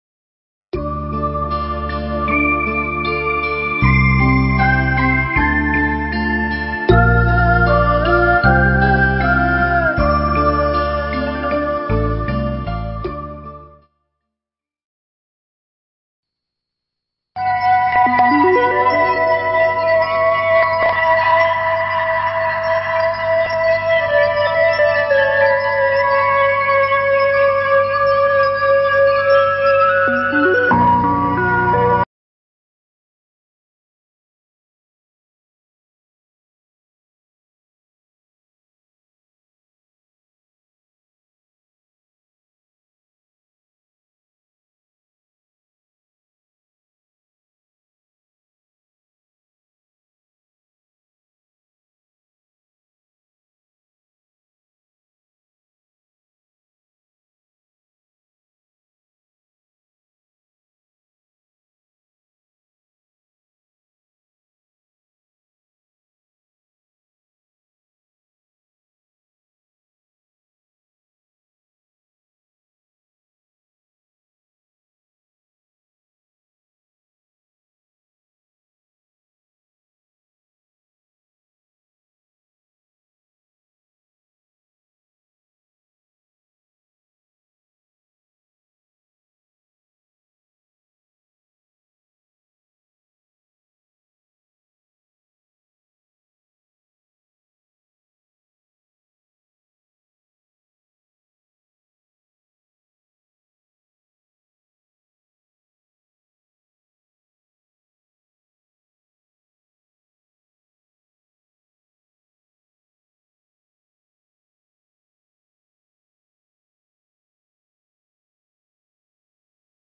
Mp3 Pháp Thoại Chủ Trương Đường Lối Ứng Dụng Tu Tại Thiền Viện Trúc Lâm 1/3 – Hòa Thượng Thích Thanh Từ ngày 11 tháng 9 năm 1996, (ngày 29 tháng 7 năm Bính Tý)